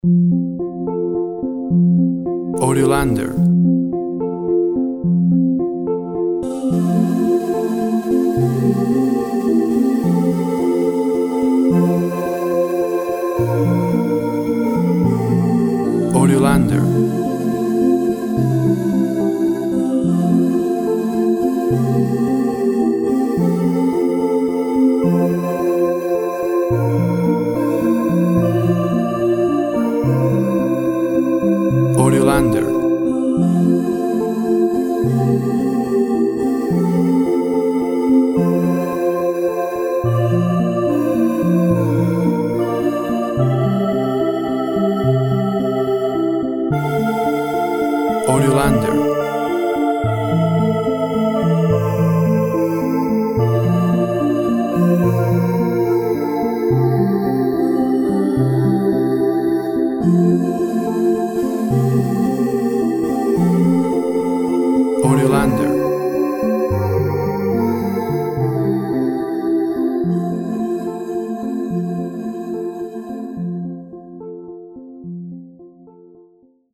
Calming synth instrumental with holiday sentiment.
Tempo (BPM) 72